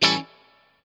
CHORD 7   AC.wav